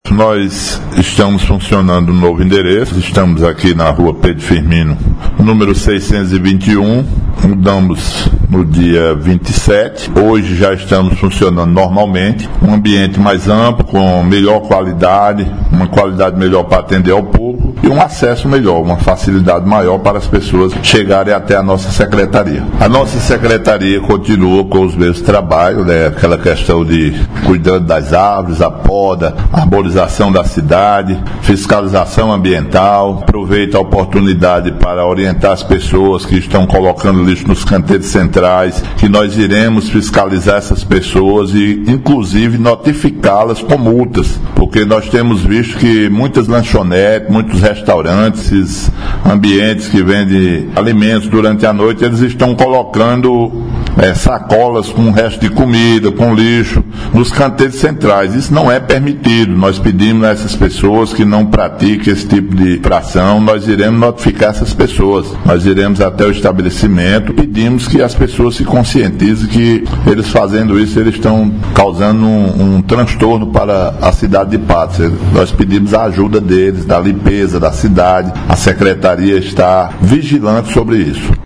Fala do secretário de Meio Ambiente – Natércio Alves –